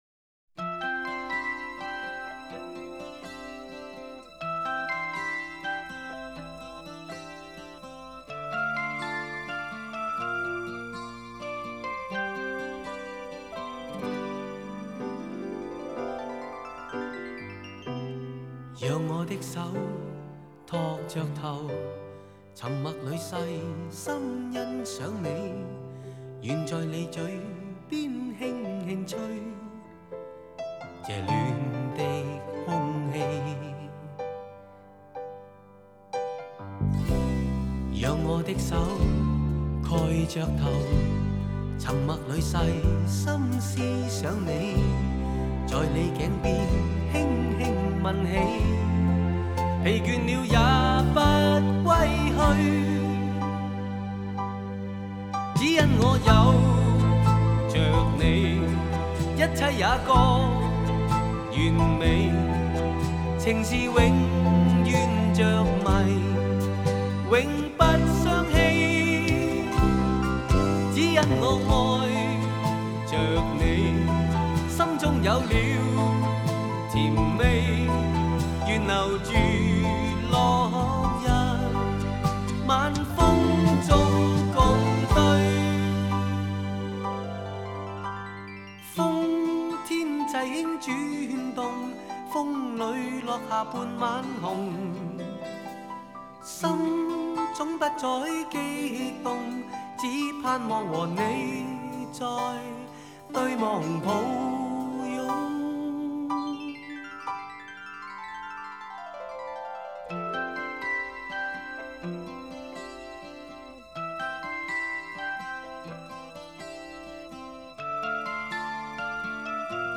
1980年代末期发布，旋律温柔，歌词深情。